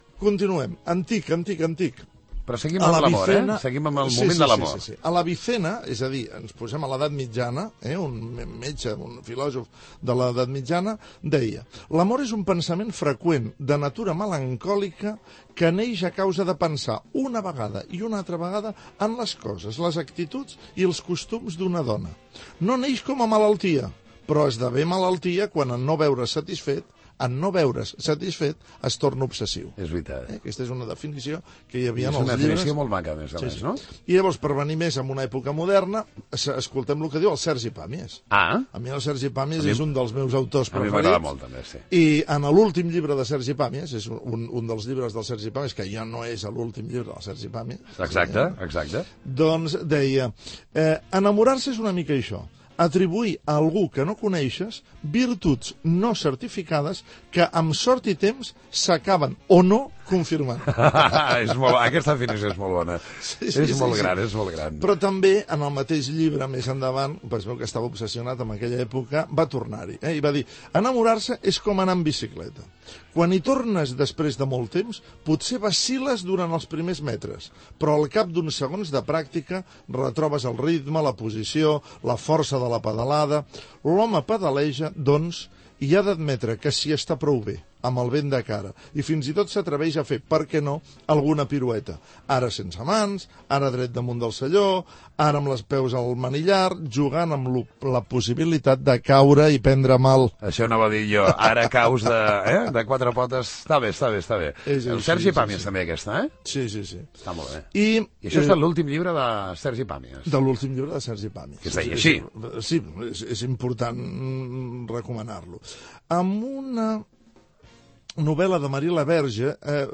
Gènere radiofònic Informatiu
Banda FM